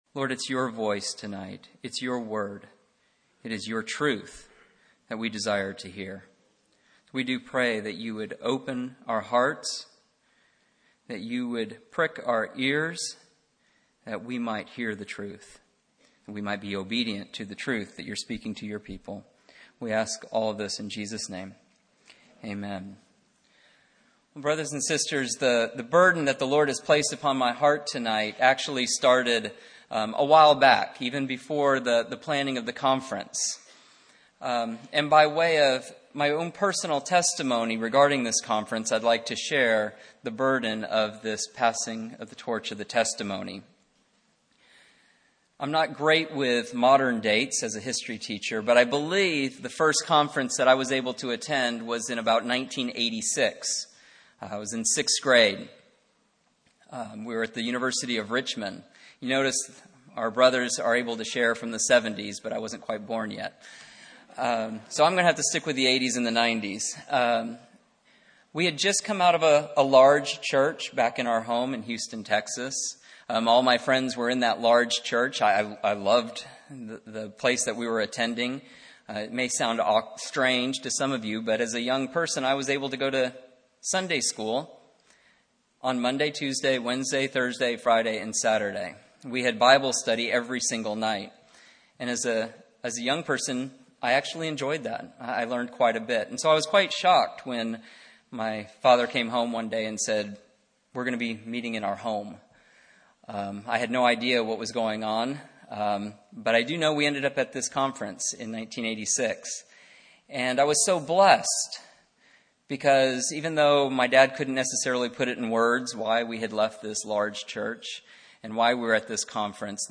Christian Family Conference We apologize for the poor quality audio
Message